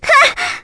Viska-Vox_Damage_02.wav